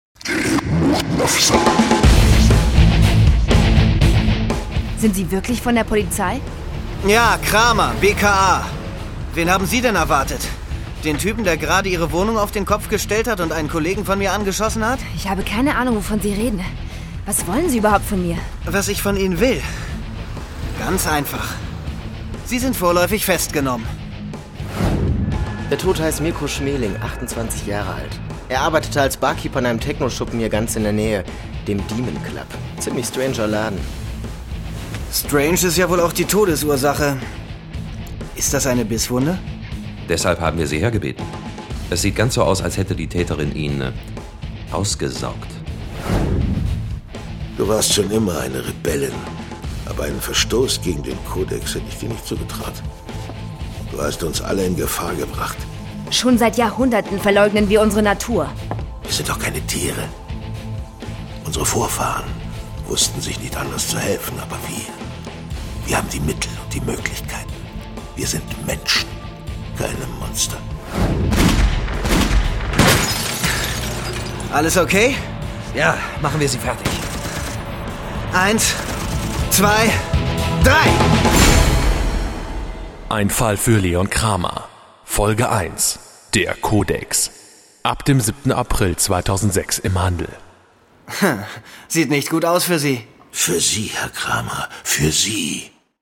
Sprecher: Fabian Harloff,